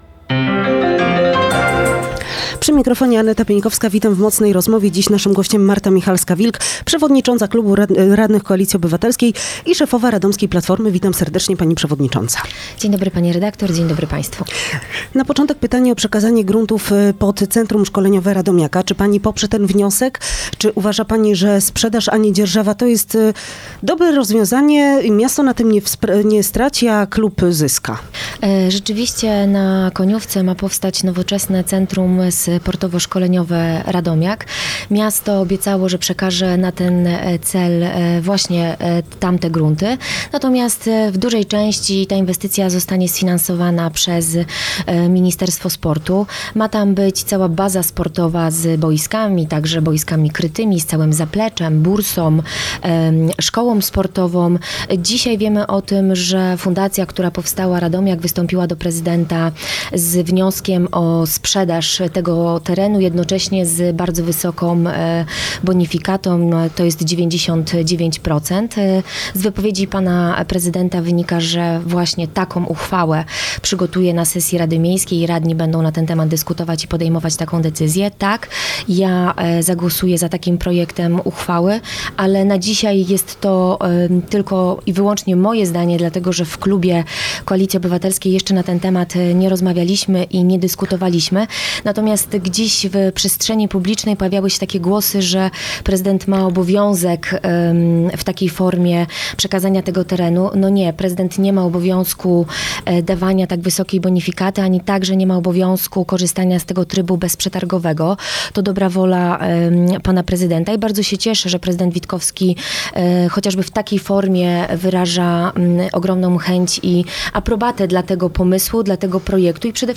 Przewodnicząca klubu radnych PO, Marta Michalska – Wilk była gościem